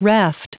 Prononciation du mot raft en anglais (fichier audio)
Prononciation du mot : raft